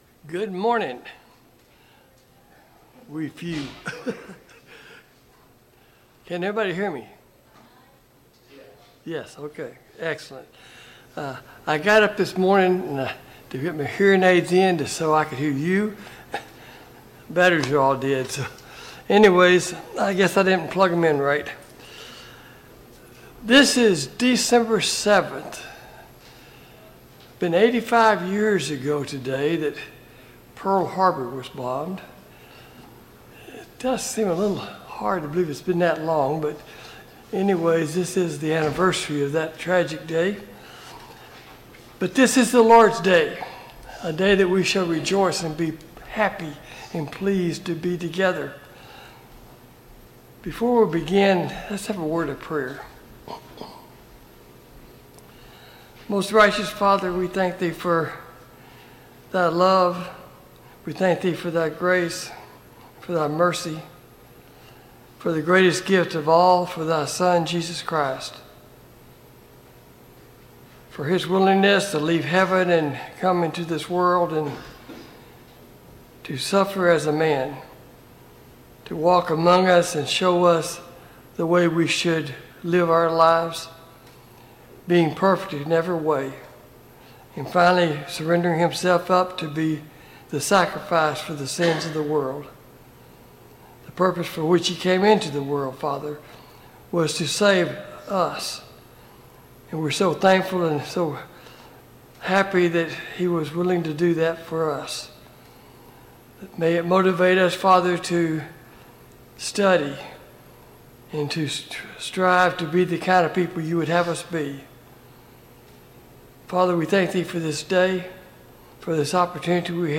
Passage: Mark 11-12 Service Type: Sunday Morning Bible Class